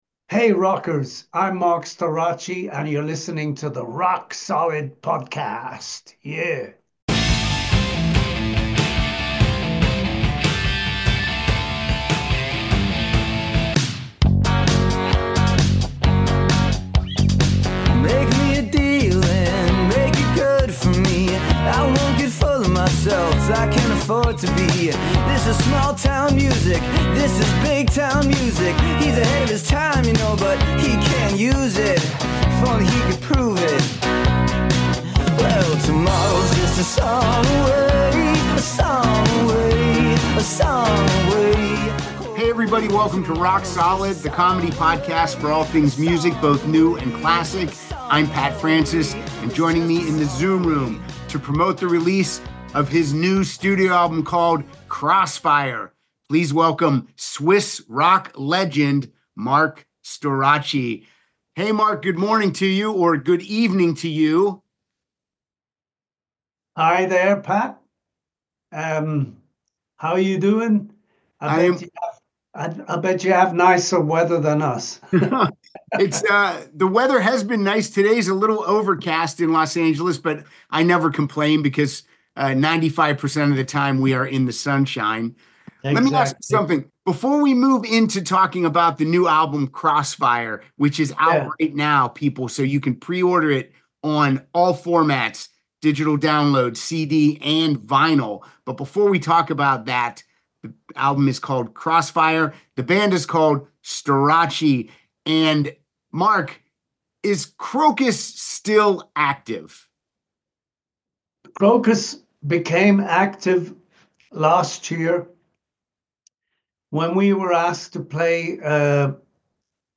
vocalist Marc Storace to the show to discuss his career with Krokus and promote the new STORACE album called "Crossfire."